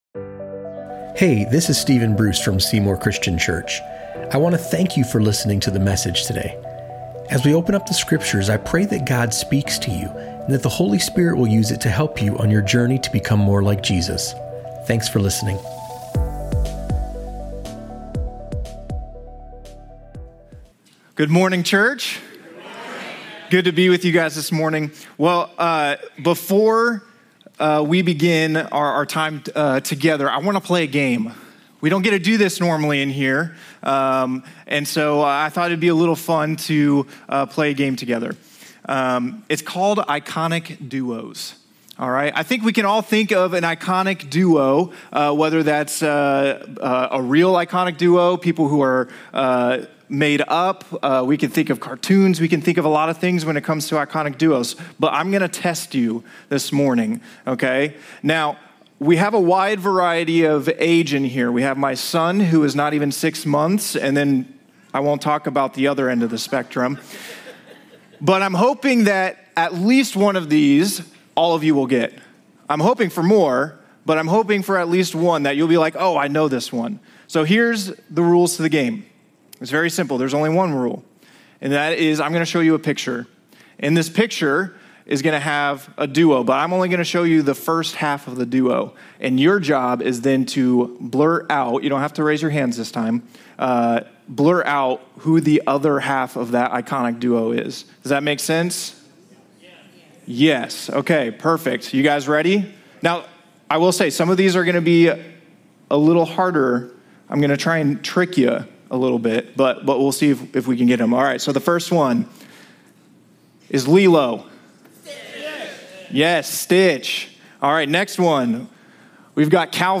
In this week’s message from Proverbs, we learn how to choose friends who help us grow closer to Christ, avoid friendships that lead us astray, and embrace the greatest friendship of all — friendship with Jesus.